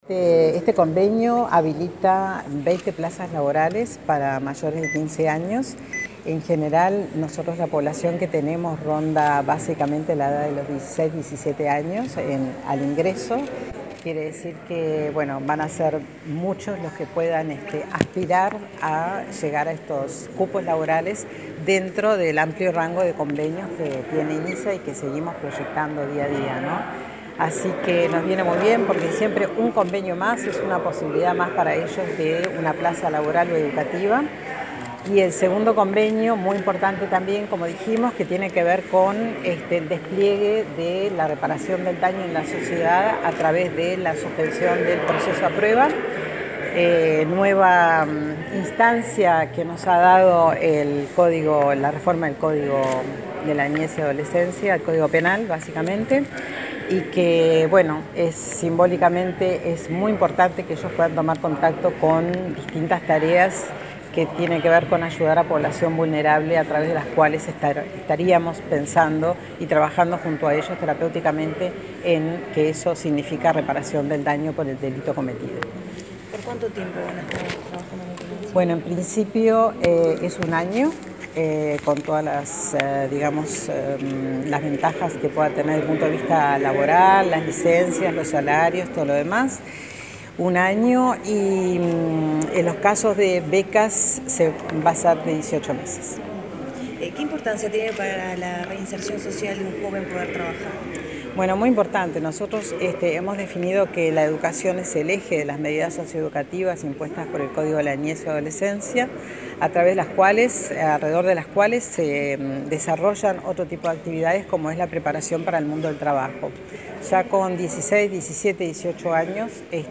Tras la firma de un acuerdo entre el Inisa y la Intendencia de Montevideo, 20 jóvenes mayores de 15 años realizarán pasantías laborales durante un año. A su vez, jóvenes de entre 13 y 18 años desarrollarán tareas comunitarias, en el marco de la reforma del Código del Niño y el Adolescente, explicó la presidenta de Inisa, Gabriela Fulco.